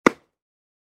hitsound0.wav